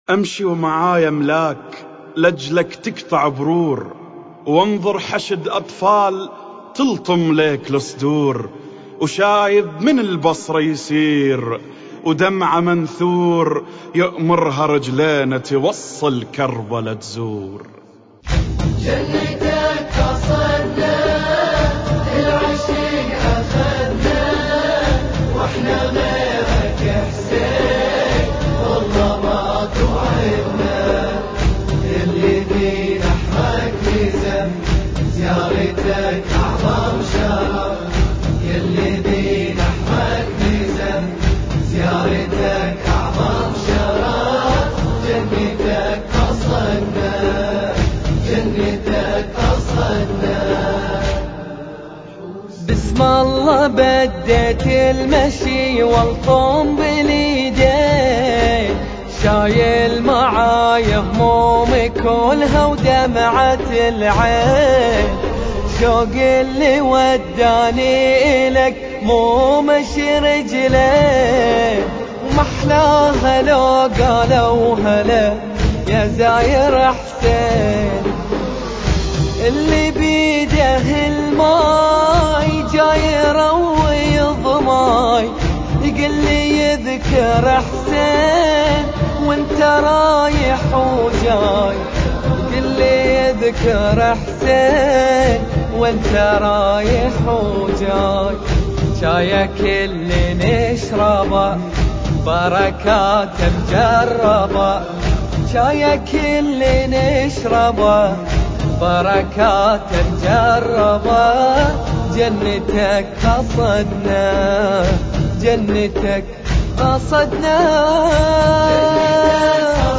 المراثي